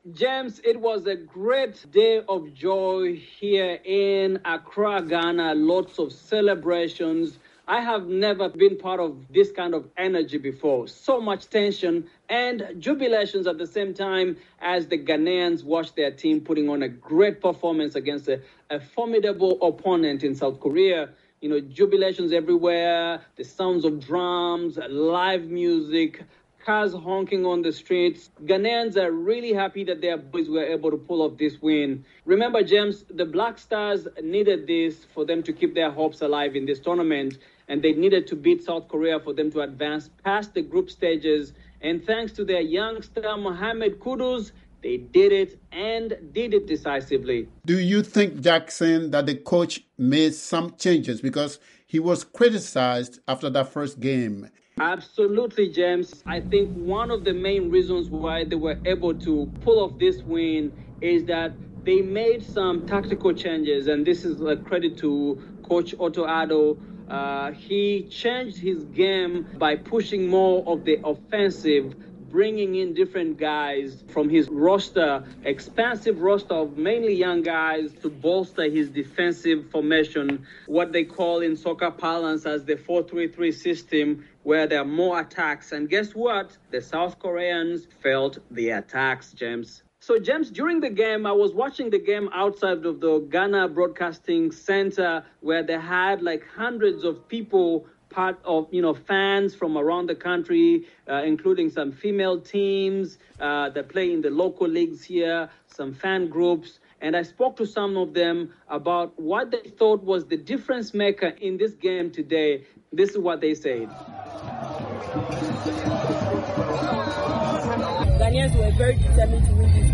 The Indomitable Lions of Cameroon came from 3-1 down to draw 3-3 with Serbia while the Black Stars of Ghana beat South Korea 3-2. Daybreak Africa Host